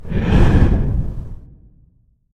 exhale.ogg.mp3